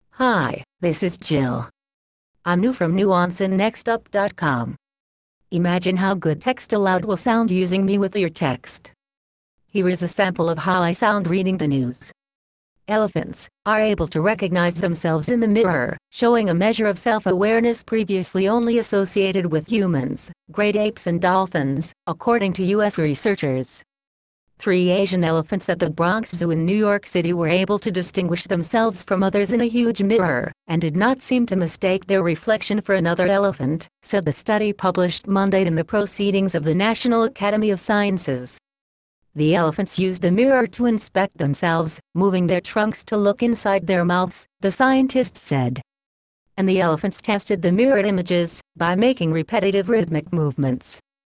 Texte de d�monstration lu par Jill (Nuance RealSpeak; distribu� sur le site de Nextup Technology; femme; anglais)